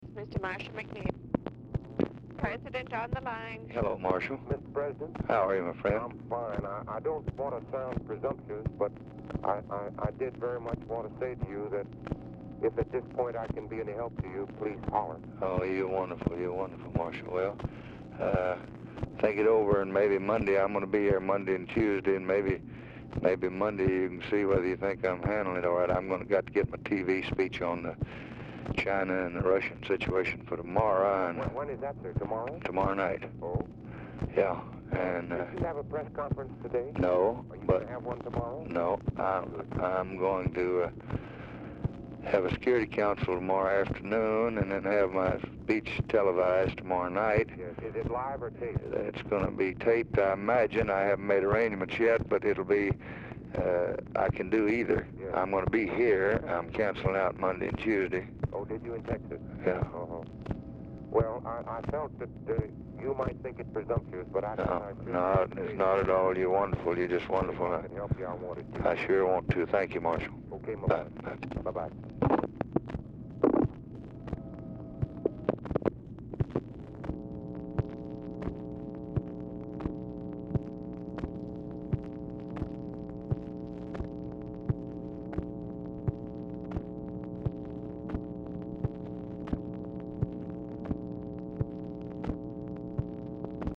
Telephone conversation
Format Dictation belt
Oval Office or unknown location